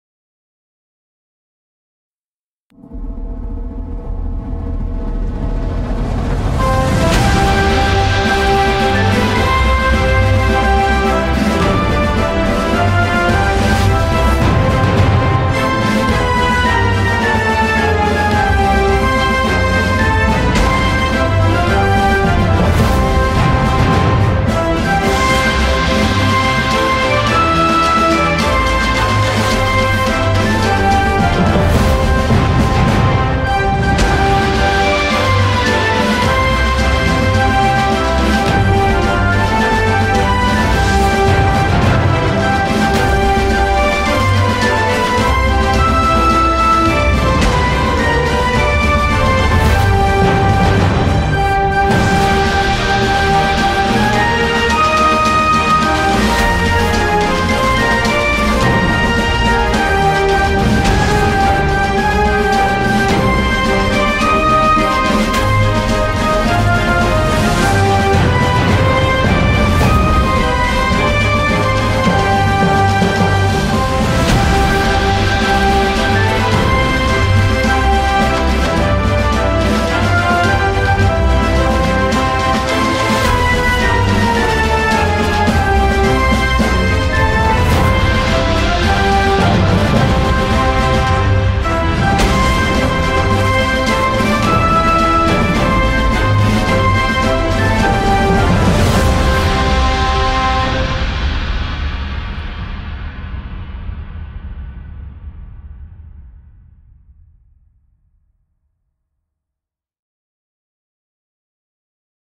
Эпическая оркестровая версия гимна Мексики без слов